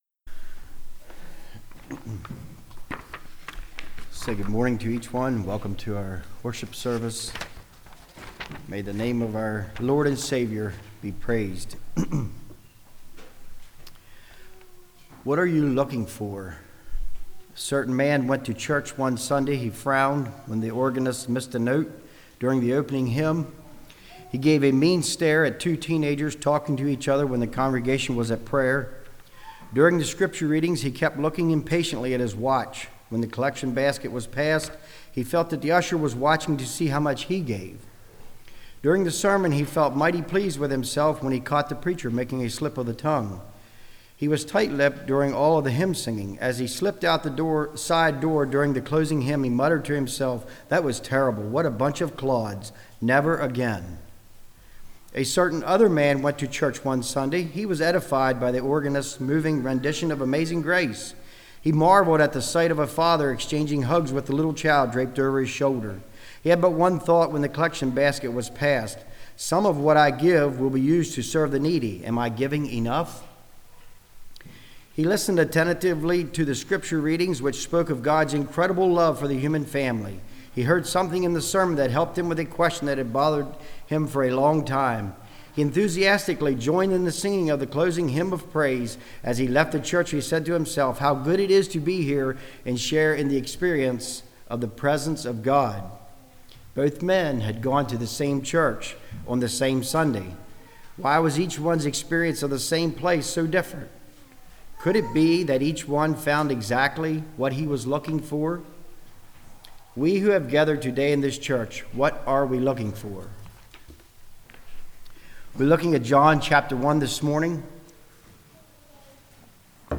John 1:35-51 Service Type: Message Bible Text